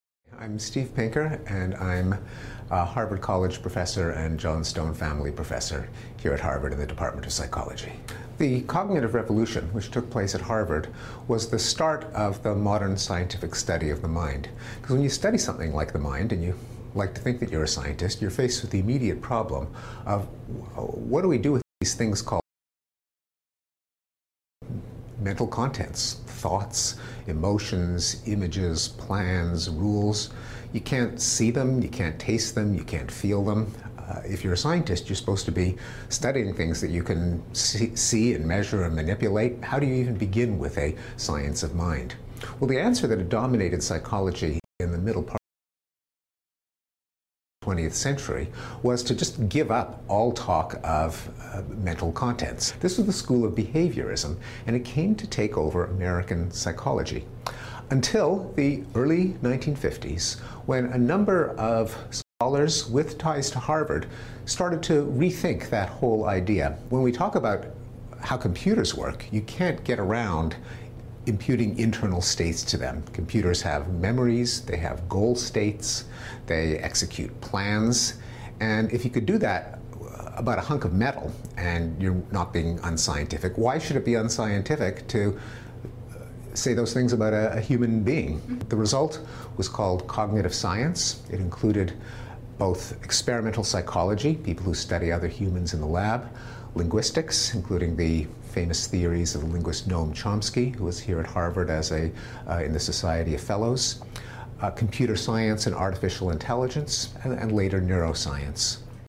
You will hear an Interview/Lecture.
The speaker, Steven Pinker, a Harvard professor, discusses the cognitive revolution that transformed the study of the mind.